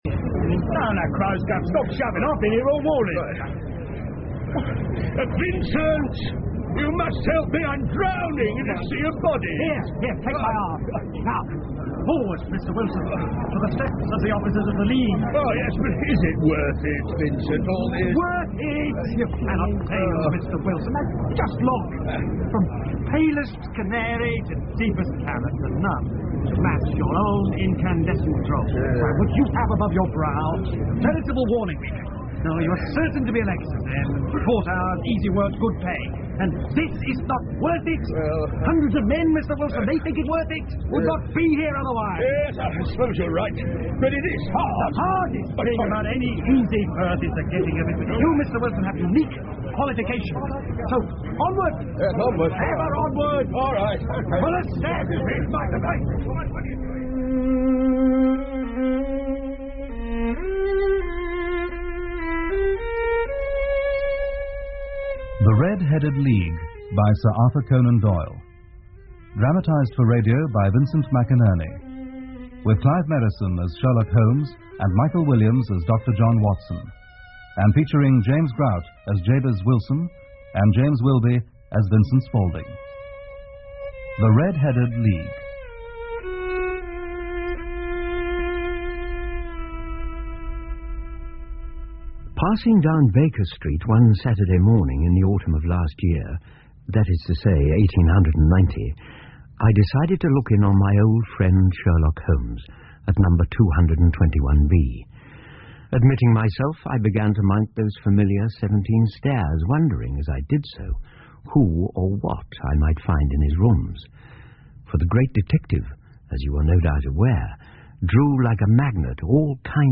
福尔摩斯广播剧 The Red Headed League 1 听力文件下载—在线英语听力室